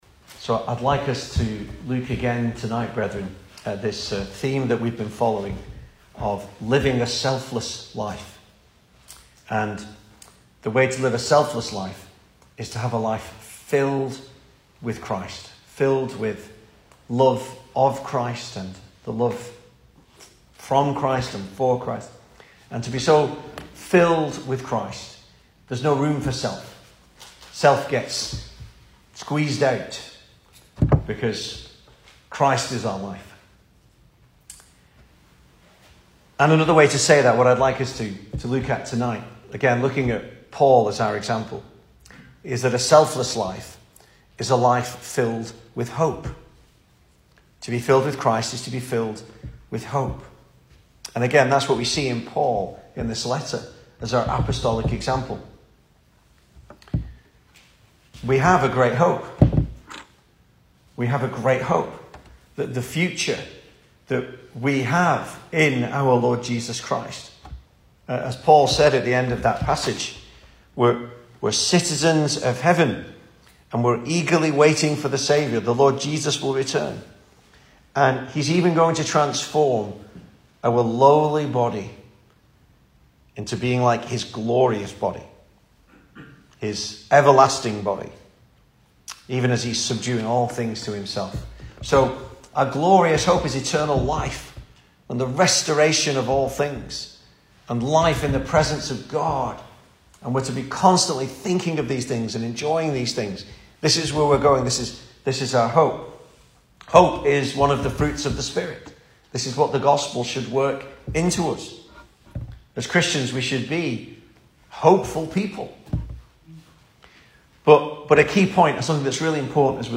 Service Type: Weekday Evening